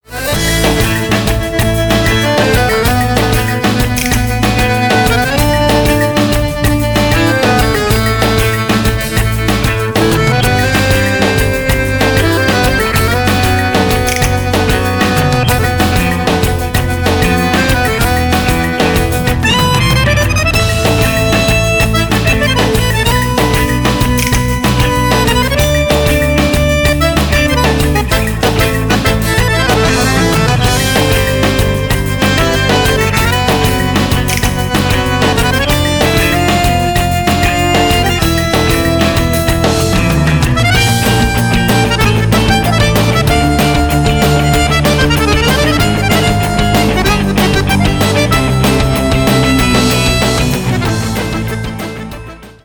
аккордеон